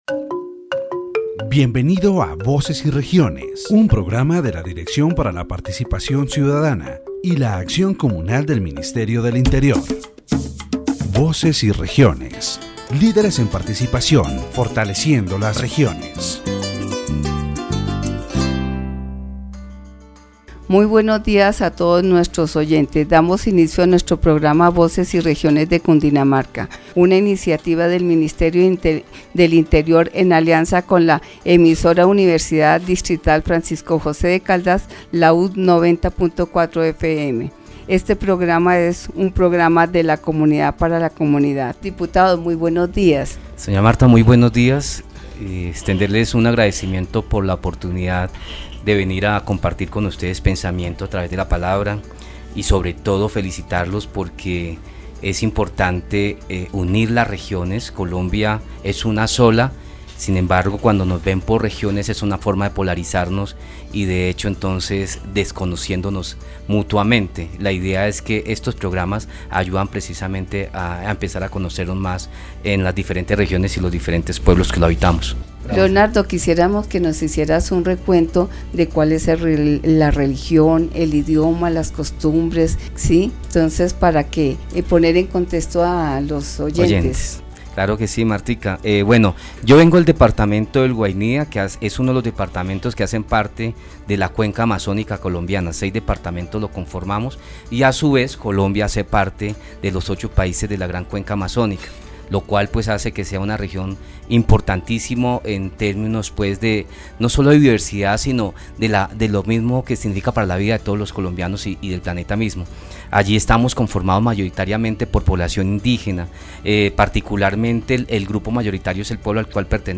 The radio program "Voices and Regions" of the Directorate for Citizen Participation and Communal Action of the Ministry of the Interior focuses on the culture and traditions of the department of Guainía. The guest, Leonardo Ladino Gutiérrez, deputy of Guainía, shares information about daily life, customs, the economy and the challenges faced by indigenous communities in this region. Topics are discussed such as the importance of the chagra (conuco) for food security, the influence of evangelization on cultural traditions, problems related to mining and the exploitation of natural resources, and the need to strengthen the cultural identity and autonomy of indigenous communities.